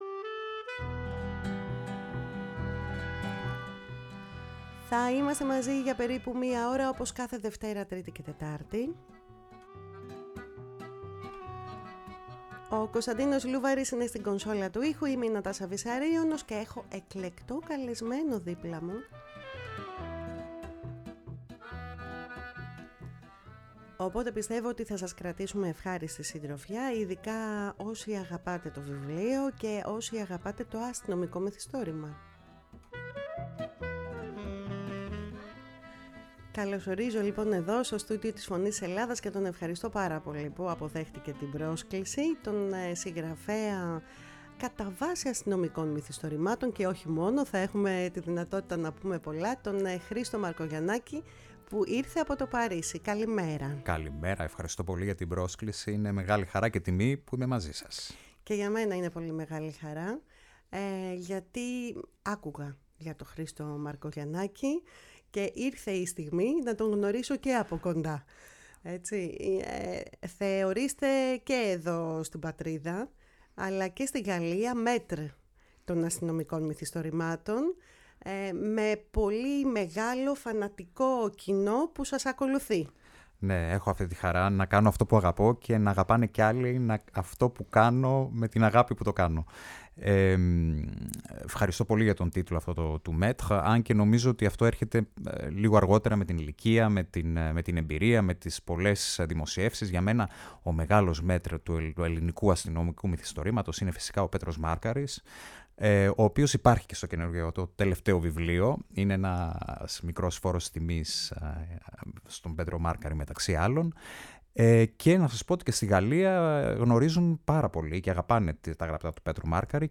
Στο στούντιο της “Φωνής της Ελλάδας“
Συνεντεύξεις